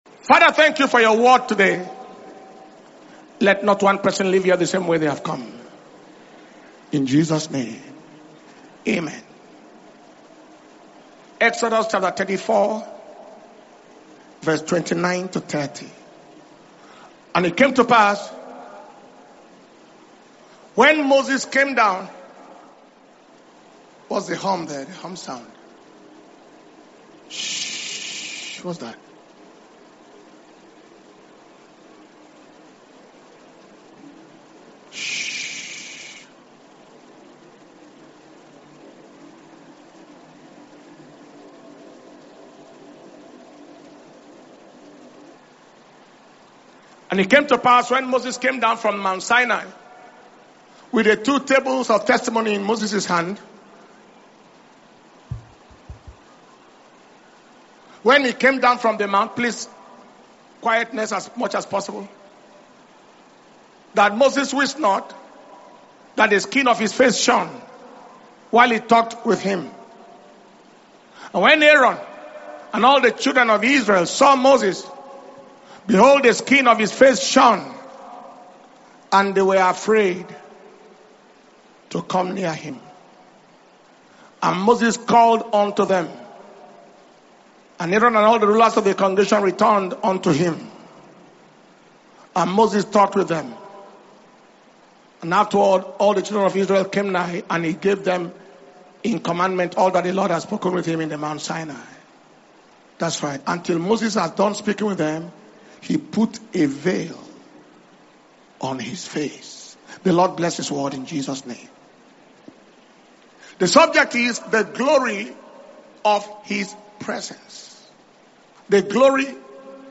November 2025 Blessing Sunday